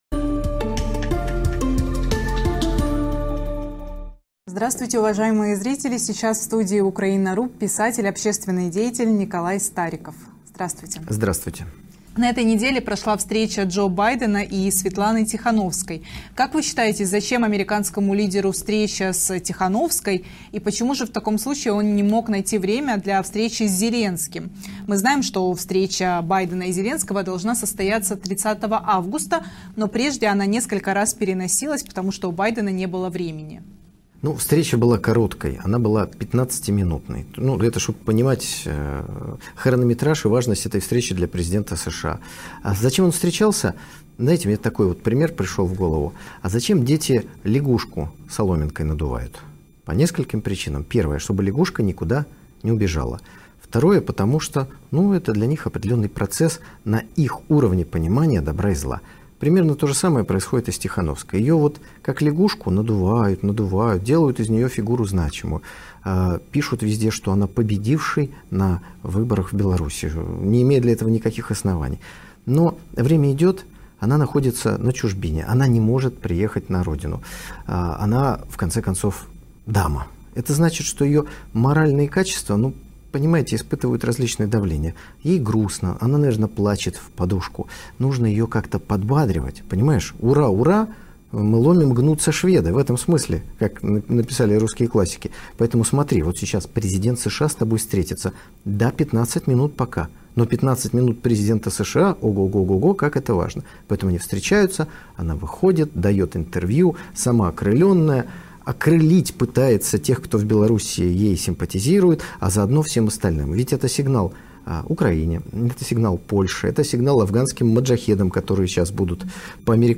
В новом выпуске интервью ресурсу «Украина.